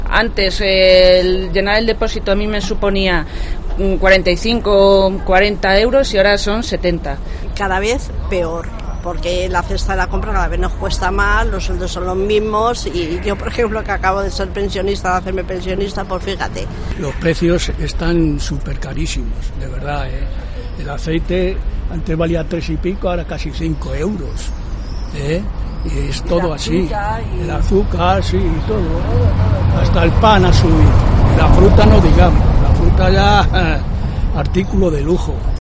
Tren de voces sobre la subida del IPC